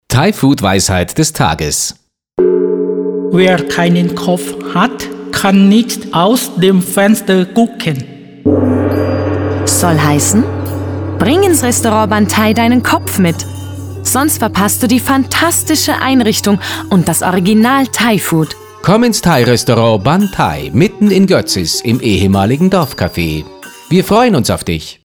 Funkspots
Protagonist der Spots ist unser erfundener thailändischer Weise Mai Pen Rai (übersetzt: „Macht doch nichts“) – und er gibt thailändische Sprichwörter zum Besten.